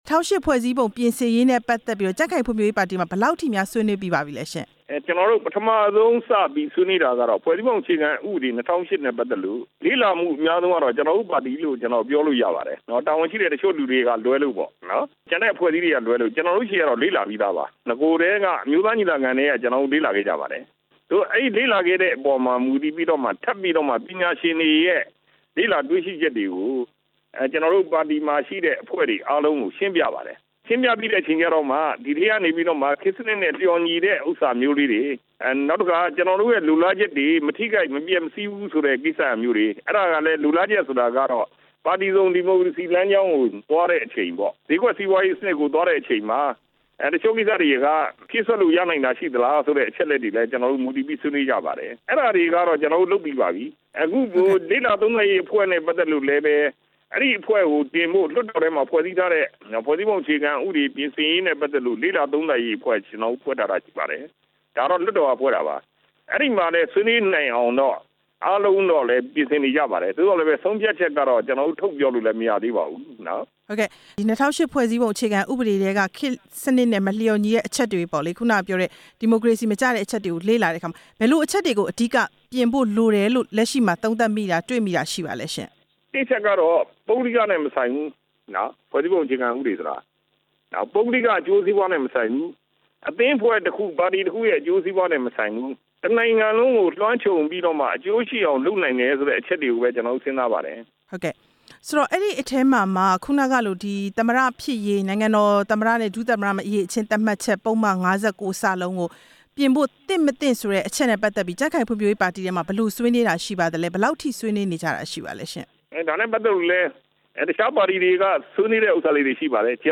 ဦးဌေးဦးနဲ့ မေးမြန်းချက် နားထောင်ရန်